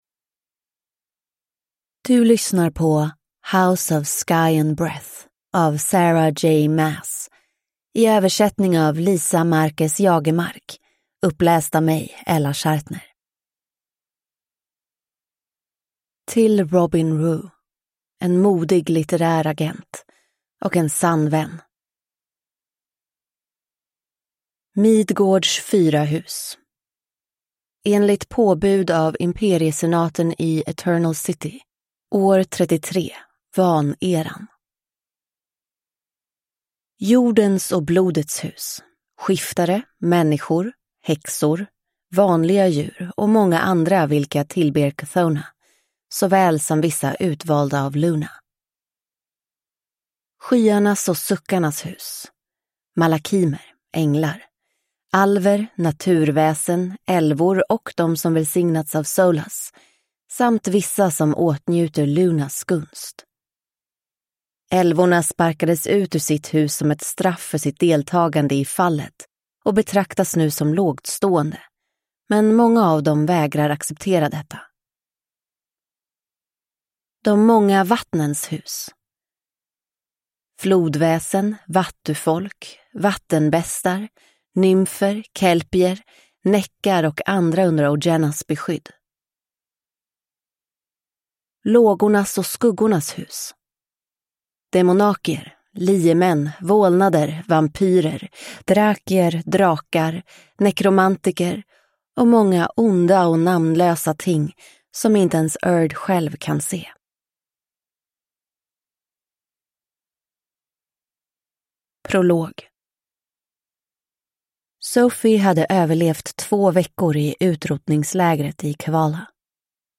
House of Sky and Breath (Svensk utgåva) – Ljudbok